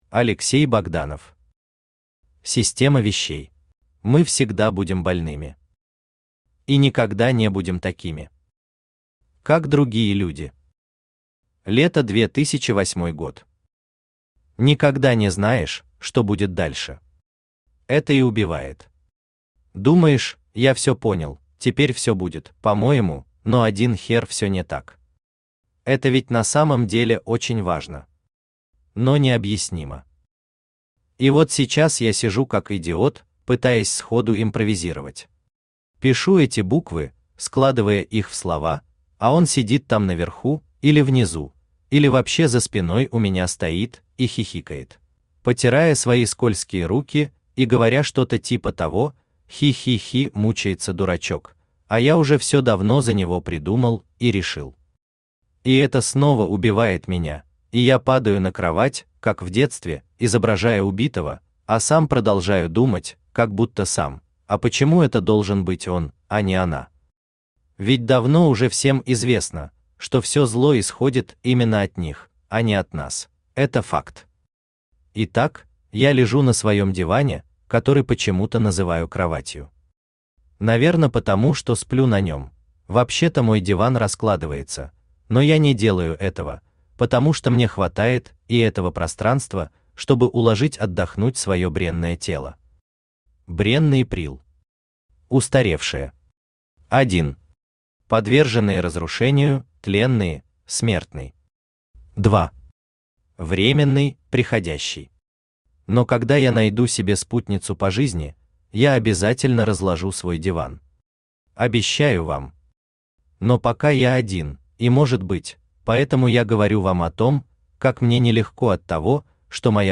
Аудиокнига Система вещей | Библиотека аудиокниг
Aудиокнига Система вещей Автор Алексей Богданов Читает аудиокнигу Авточтец ЛитРес.